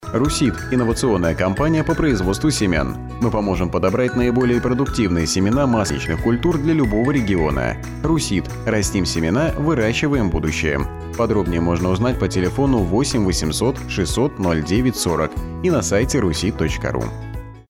Стартовало размещение рекламы на радиостанции "Европа плюс" компании "RuSeed" в г. Оренбурге.